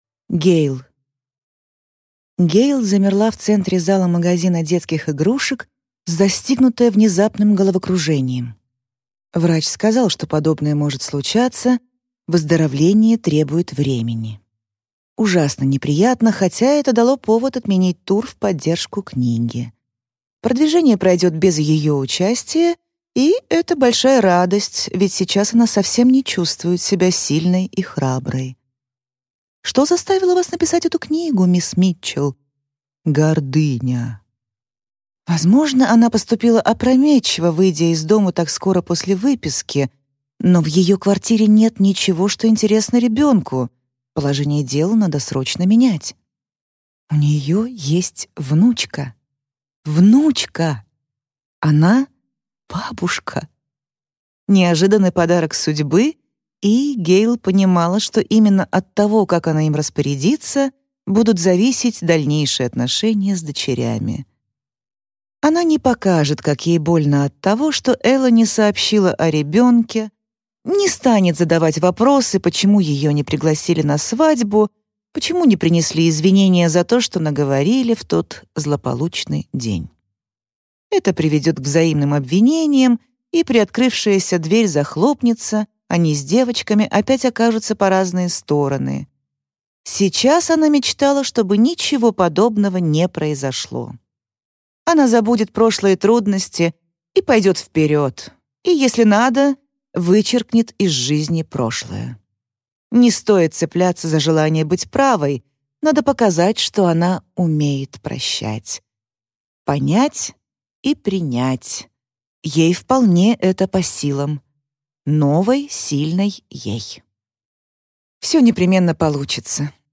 Аудиокнига Наше худшее Рождество | Библиотека аудиокниг